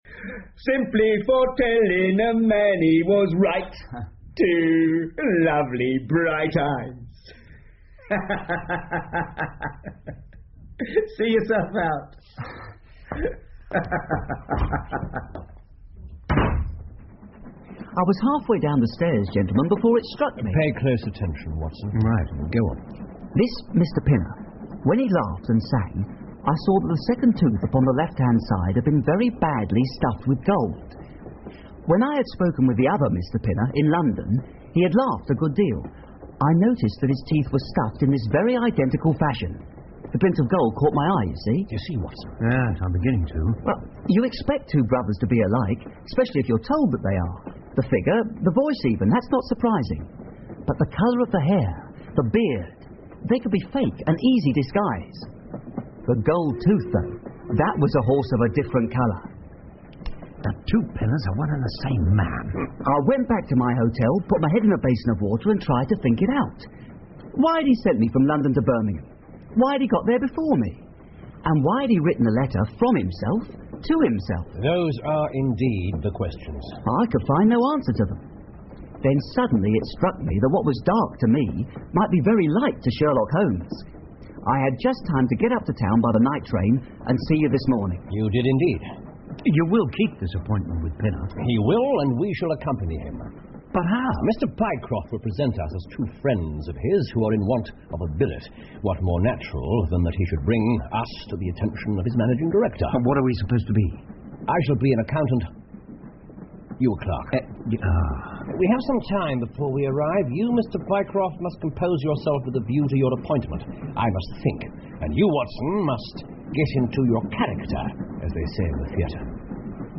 在线英语听力室福尔摩斯广播剧 The Stock Brokers Clerk 6的听力文件下载,英语有声读物,英文广播剧-在线英语听力室